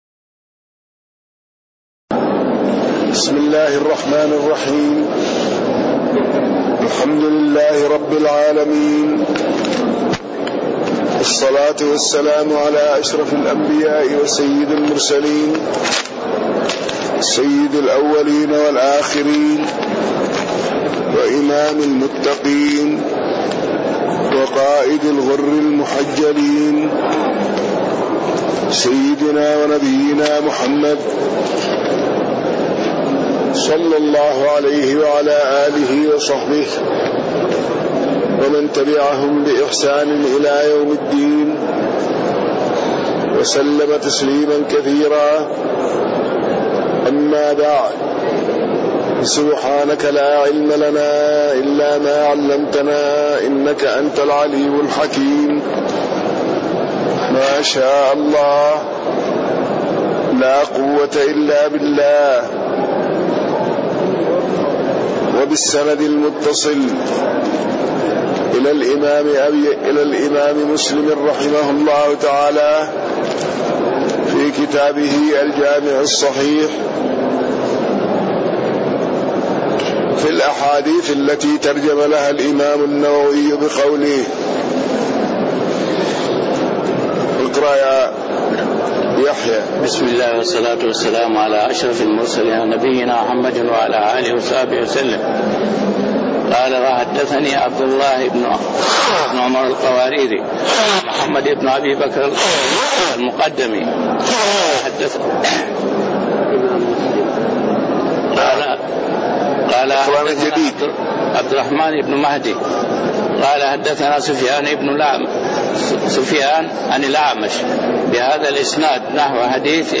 تاريخ النشر ٢٨ ربيع الثاني ١٤٣٤ هـ المكان: المسجد النبوي الشيخ